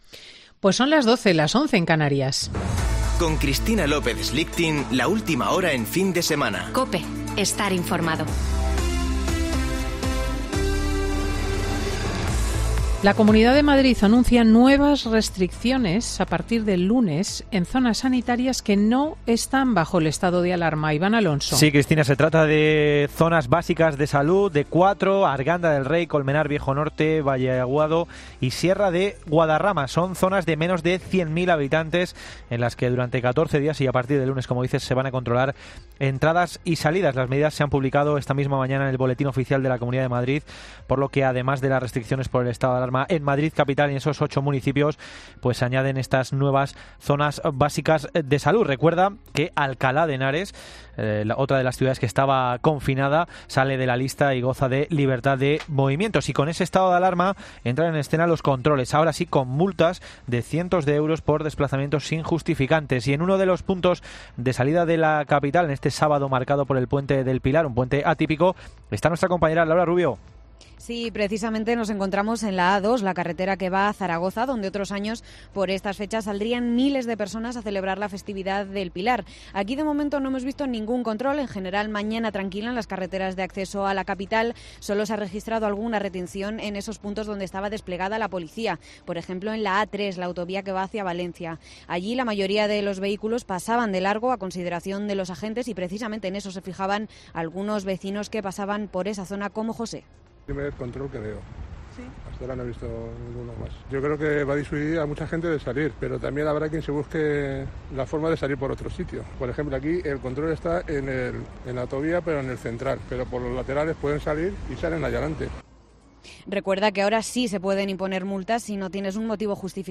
Boletín de noticias COPE del 10 de octubre de 2020 a las 12.00 horas